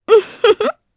Epis-Vox_Happy2_jp.wav